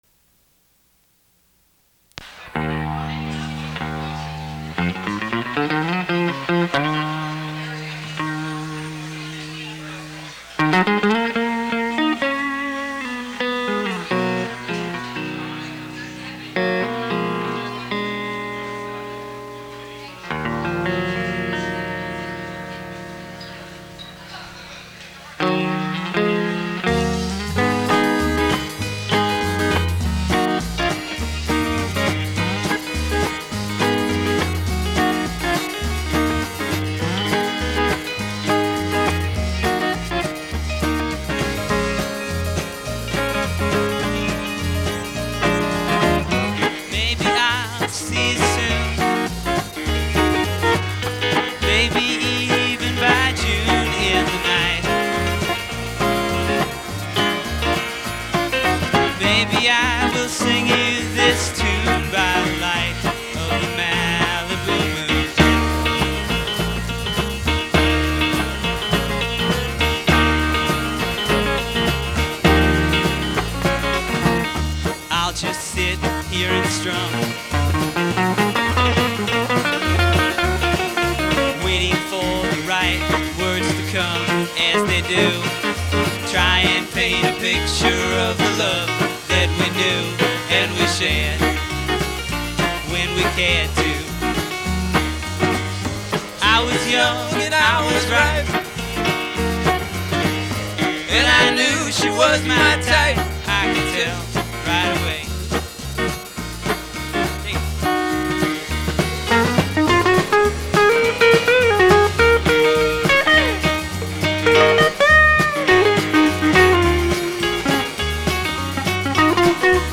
recorded live
rhythm guitar & lead vocals
piano & vocals